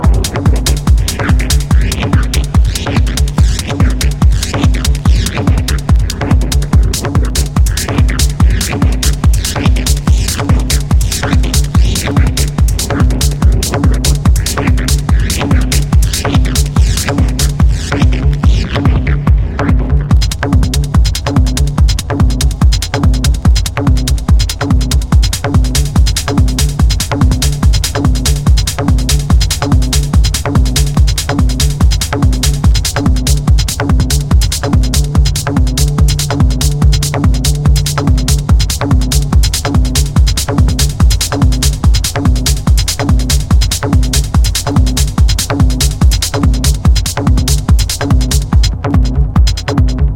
BREAKBEATS/HOUSE / TECHNO / ELECTRO CLASH
アンダーグラウンド感とおふざけ感が絶妙に調和したエレクトロ・クラッシュ人気曲！